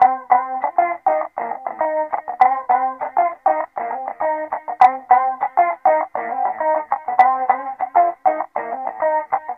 Sons et loops gratuits de guitares rythmiques 100bpm
Guitare rythmique 47